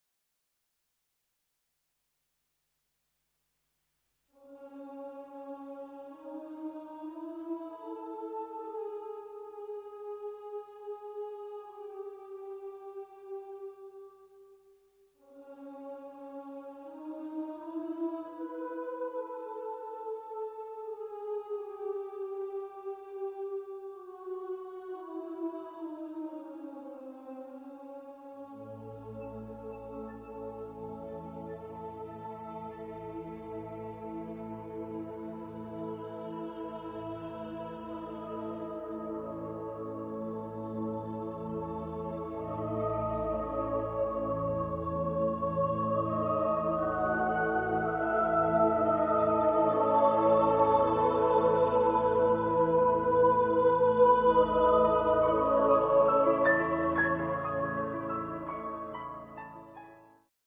un moment de douceur et détente intérieur entre Soi et Soi.